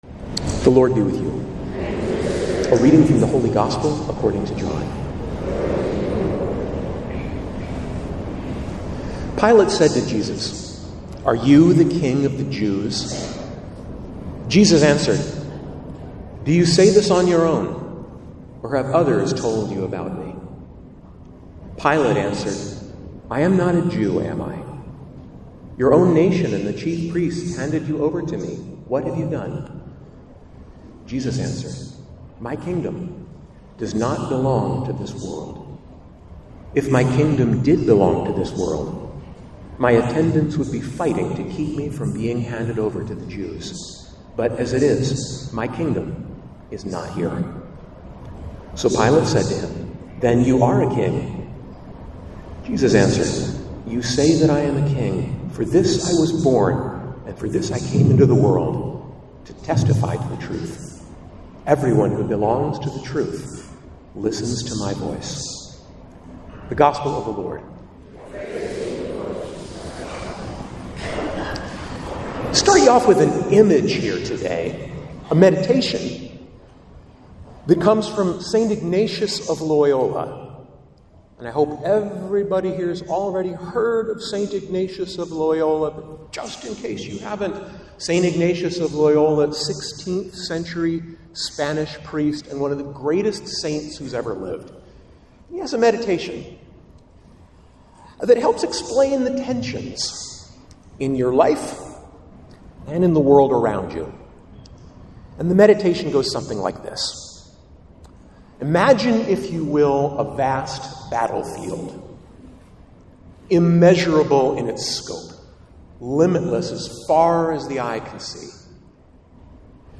Religion